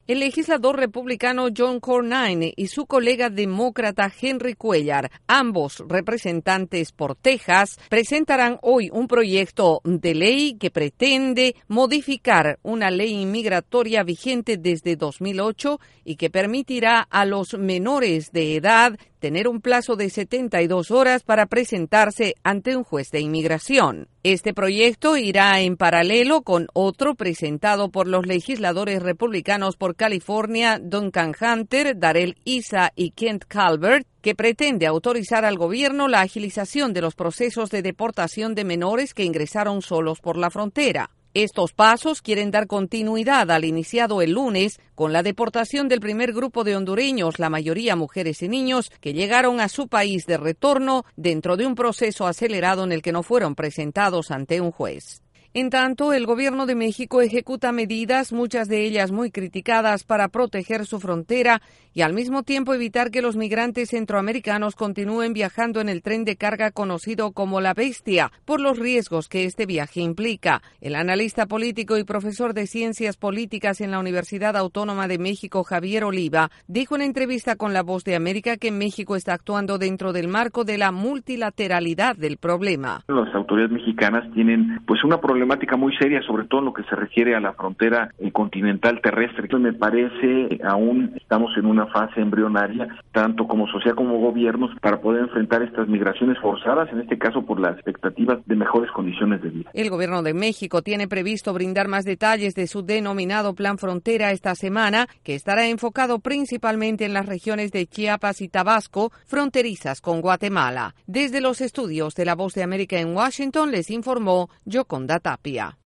La situación de los menores inmigrantes que cruzaron solos la frontera sigue siendo un proceso que busca soluciones tanto en el Gobierno como en el Congreso de Estados Unidos. Desde la Voz de América en Washington DC informa